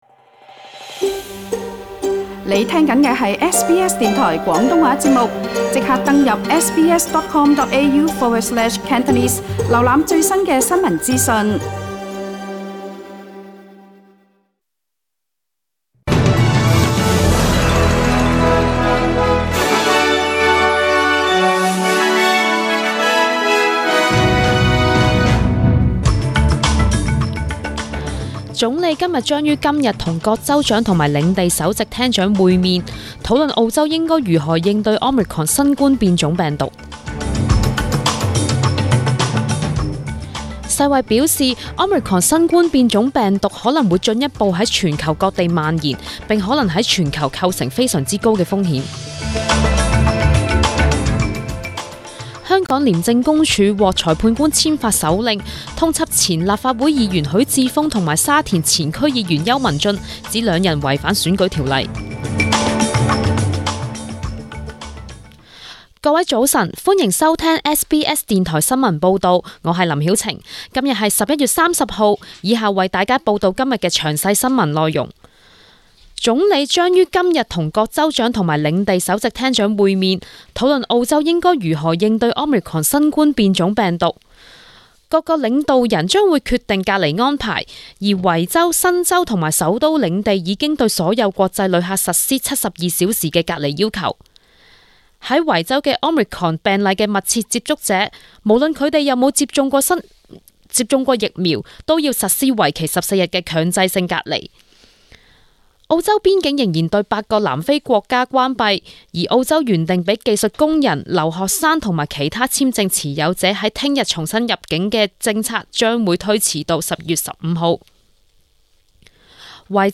SBS中文新聞(11月30日)